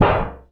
metal_tin_impacts_hit_hard_06.wav